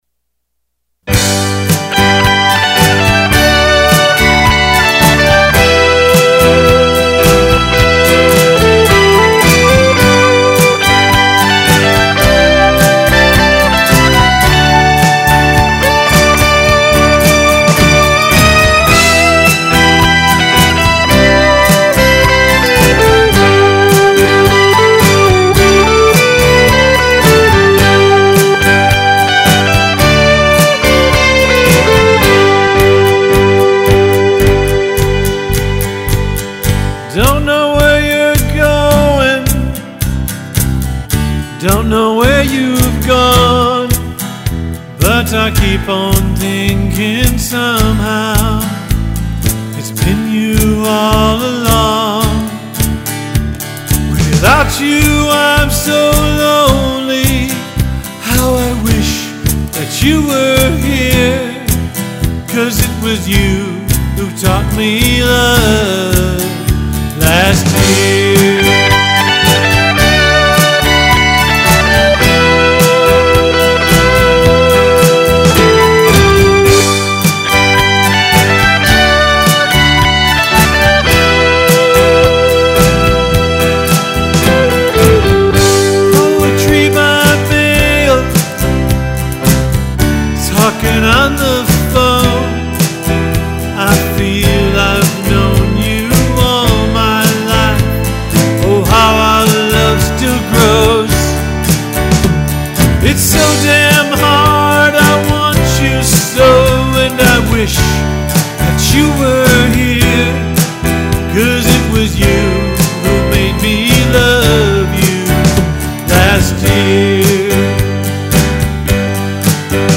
guitar solos